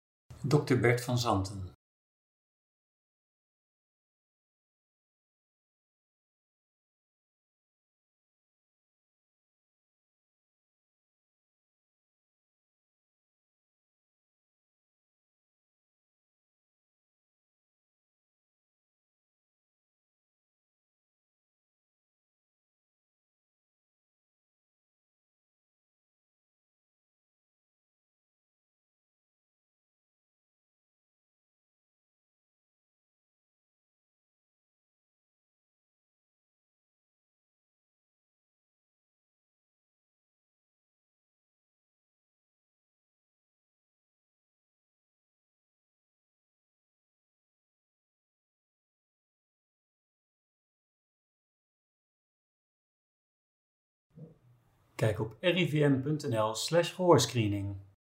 De audioloog